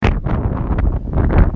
风声 " wind18
描述：风大风暴